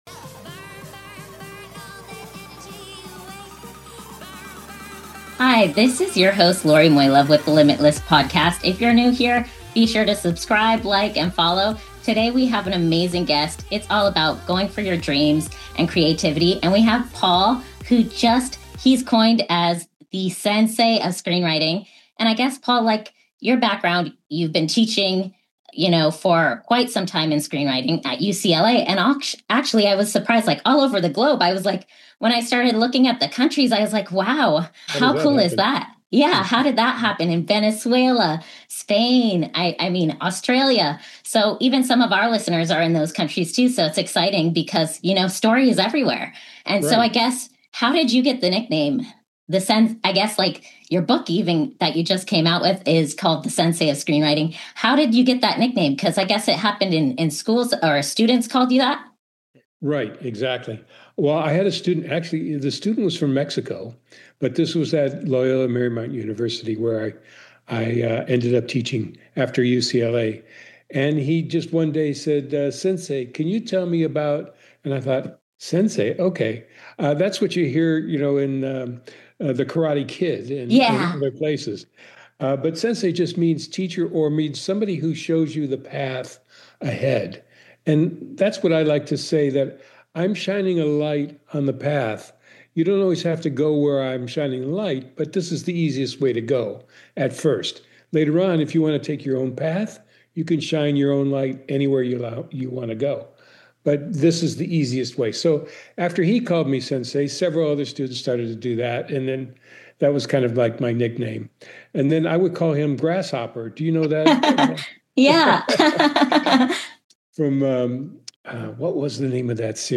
compelling conversation